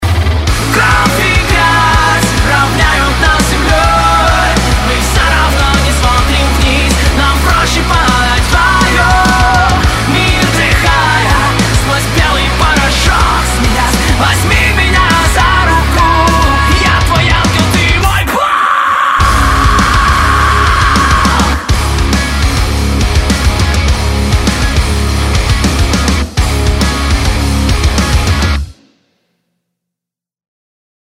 громкие
Драйвовые
Alternative Metal
nu metal
русский рок